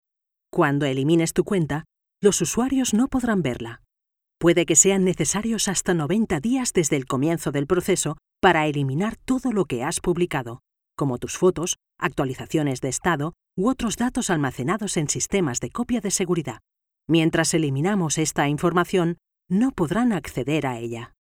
I have a versatile voice that can make different voices, and very different intentions in the same voice, if necessary.
Sprechprobe: eLearning (Muttersprache):
My recording equipment is: Neumann condenser microphone TLM 102, Previous Fucusrite Scarlet 2/2 and Software logic pro X and Audition.